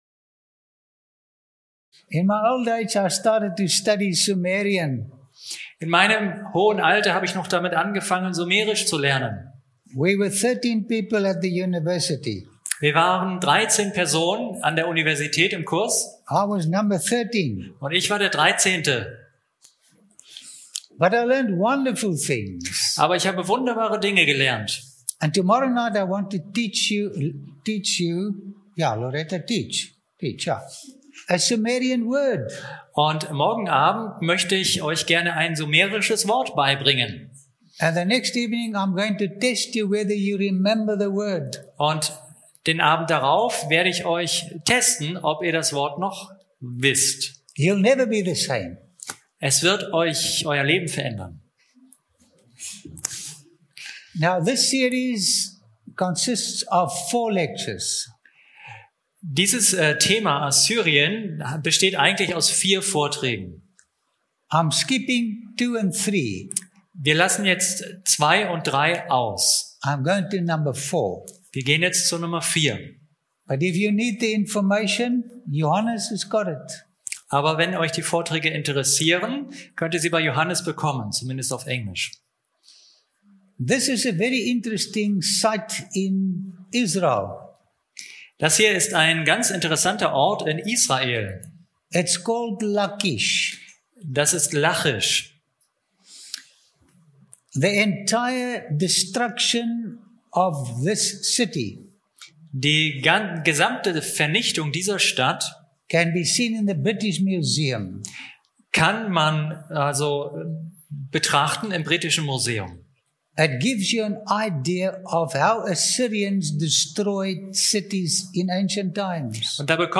In den reich bebilderten Vorträgen erhält der Zuschauer nicht nur faszinierende Einblicke in vergangene Kulturen, sondern begegnet Menschen, die vor Tausenden von Jahren ganz ähnliche Freuden und Sorgen hatten wie wir – und deren ermutigende Erfahrungen auch heute noch erlebbar sind, wenn wir von ihnen lernen und den verlorenen Schatz des Vertrauens in Gott wiederfinden.